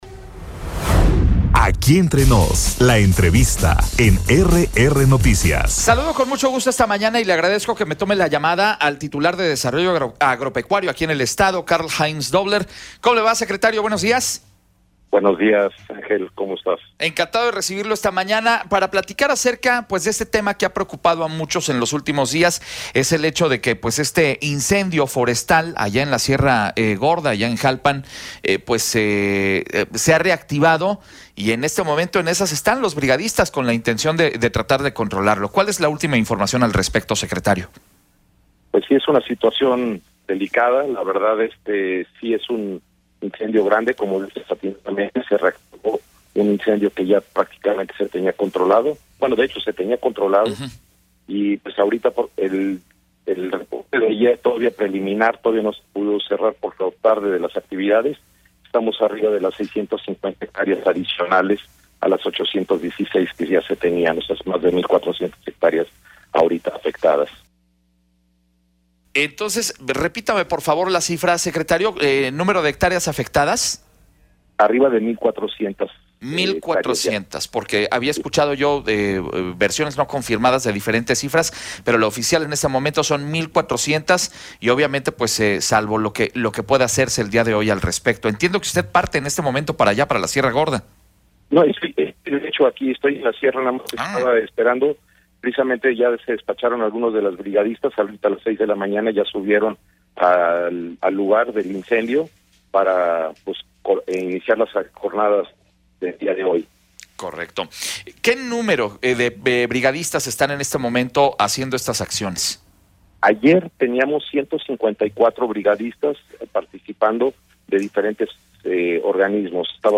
En entrevista Carl Heinz Dobler, Secretario de Desarrollo Agropecuario, habla sobre acciones para mitigar incendio en la Sierra
ENTREVISTA-CARL-HEINZ.mp3